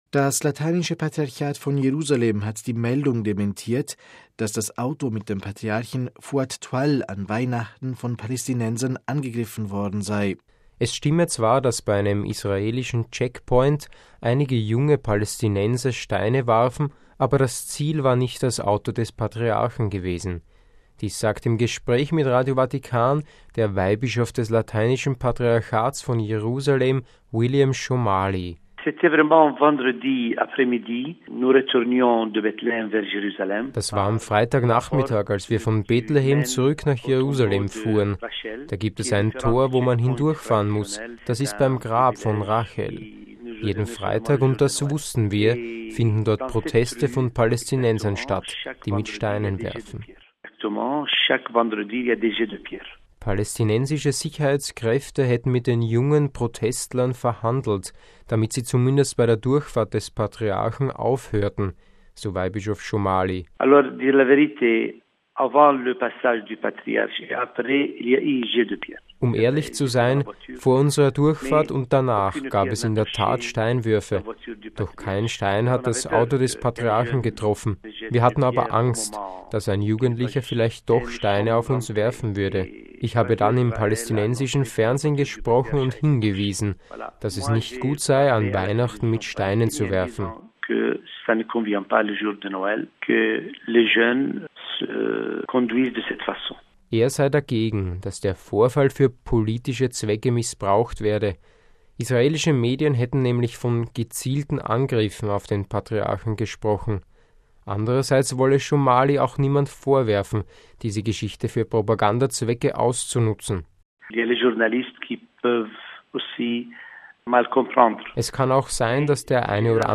Dies sagt im Gespräch mit Radio Vatikan der Weihbischof des Lateinischen Patriarchats von Jerusalem, William Shomali.